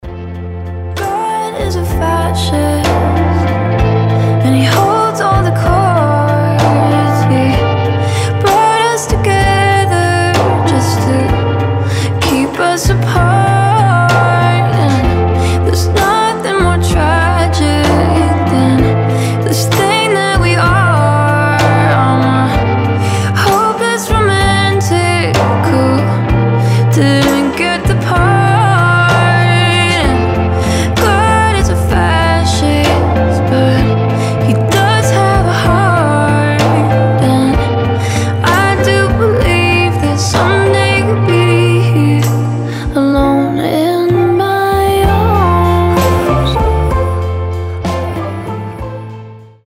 • Качество: 320, Stereo
медленные
красивый женский голос
indie pop